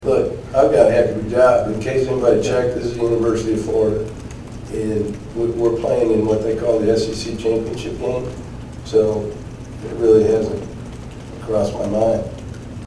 In a Wednesday press conference, McElwain was quick to quiet all rumors regarding the Oregon position.